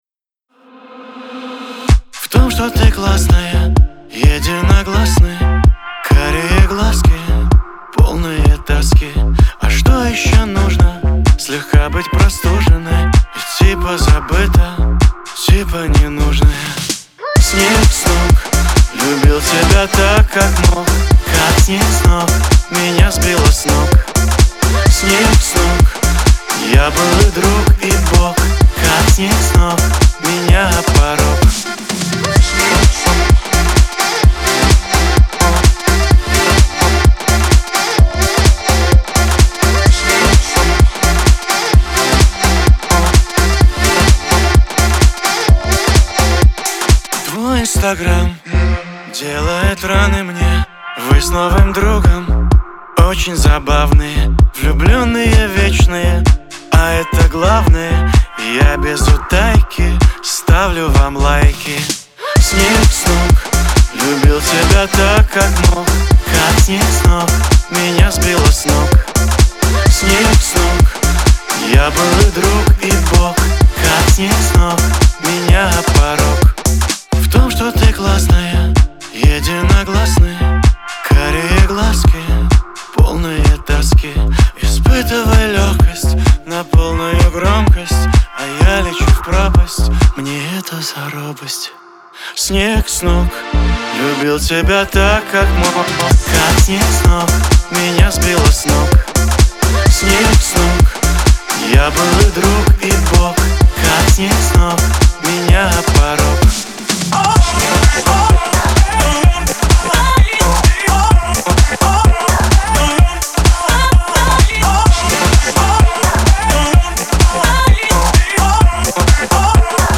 Звучание отличается мелодичностью и душевностью